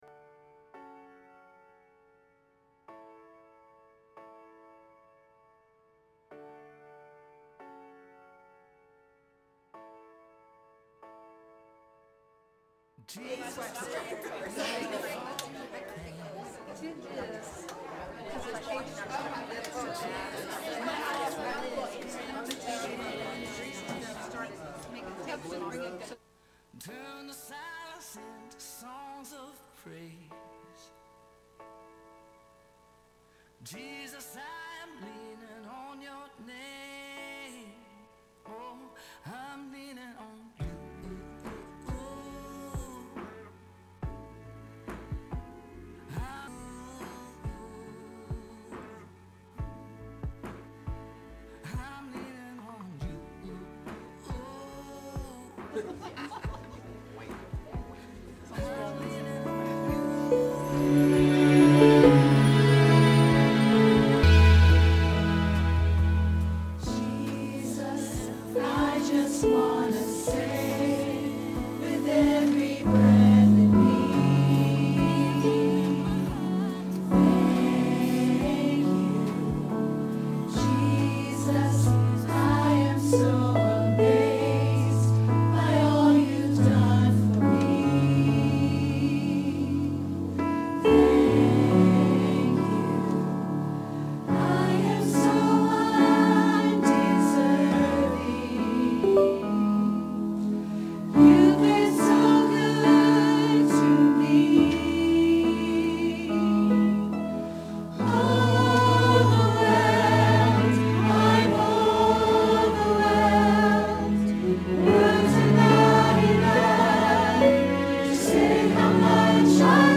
Morning Worship Service
12-nov-23-morning-worship.mp3